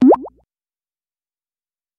Звук всплывающего окна поверх других окон (пузырь)